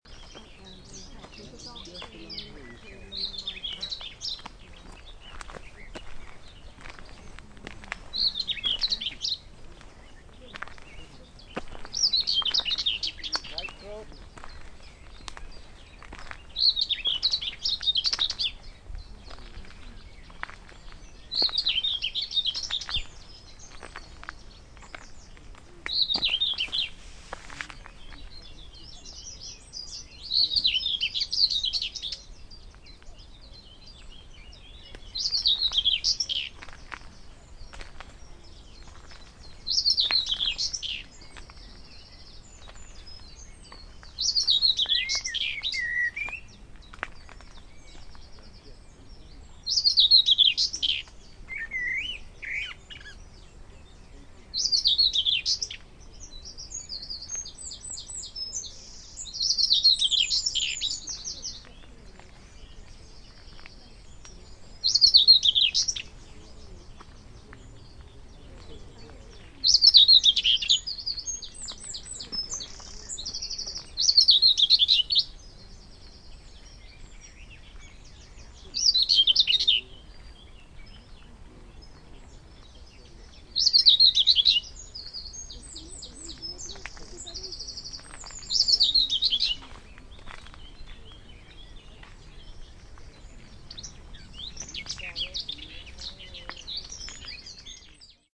South Somerset RSPB group organised a walk round Ham Wall on 17 May 2014.
The sounds (all in stereo, mp3 files):-
Garden Warbler
HamWall_Gdn_Warbler_STE-020.mp3